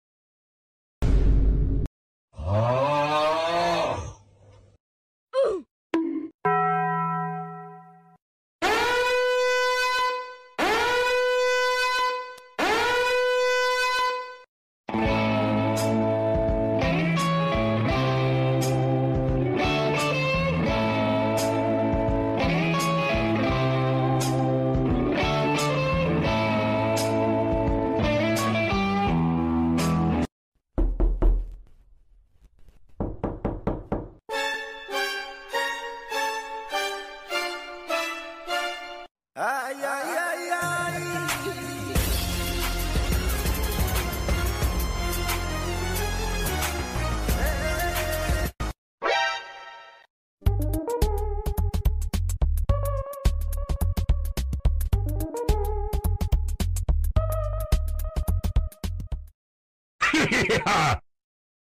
Funny meme sound effects compilation